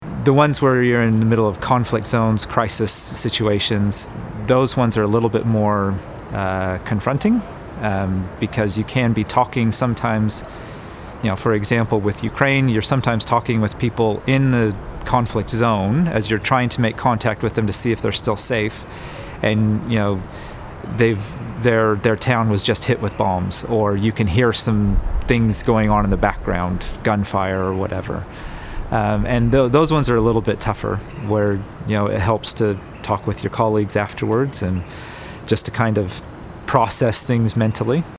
a consular officer